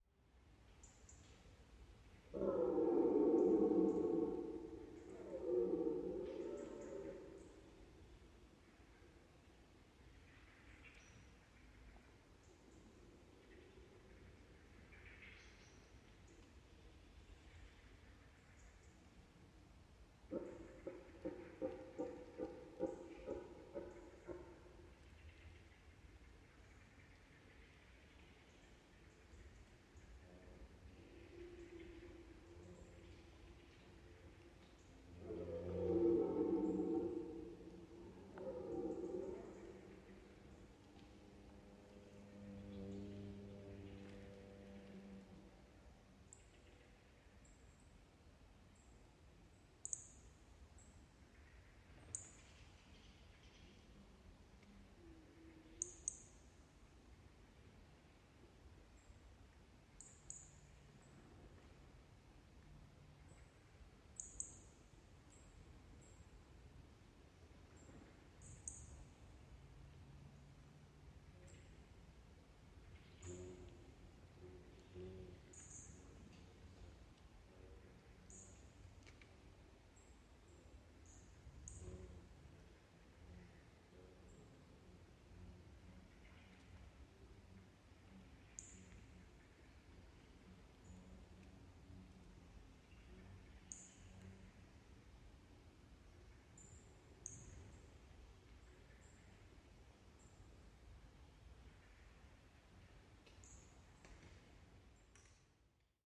Звуки ночного леса
Шум ночного леса в горах (голоса диких зверей)